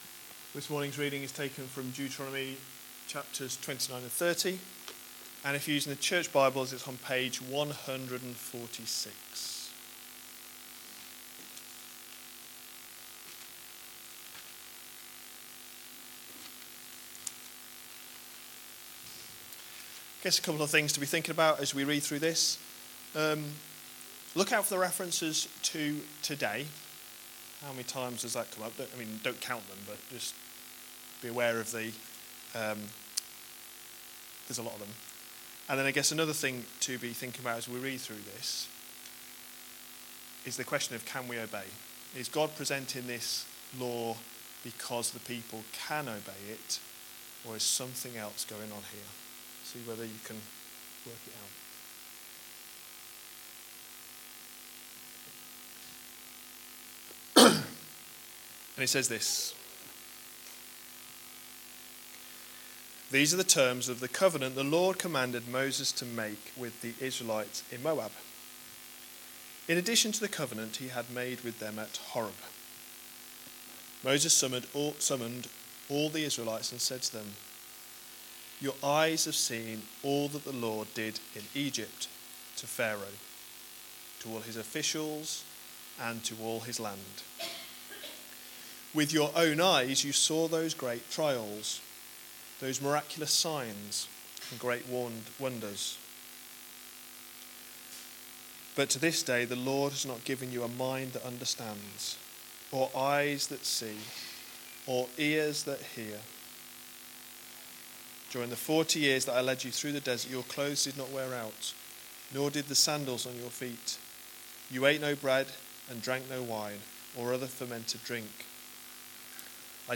A sermon preached on 20th August, 2017, as part of our Deuteronomy series.